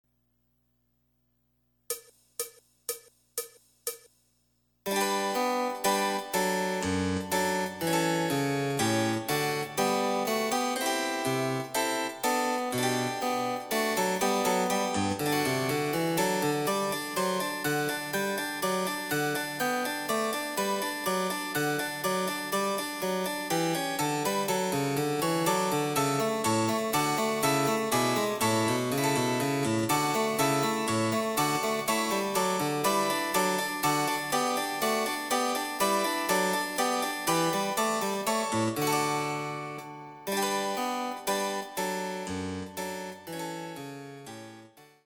★フルートの名曲をチェンバロ伴奏つきで演奏できる、「チェンバロ伴奏ＣＤつき楽譜」です。
試聴ファイル（伴奏）
第３番　ト長調
デジタルサンプリング音源使用
※フルート奏者による演奏例は収録されていません。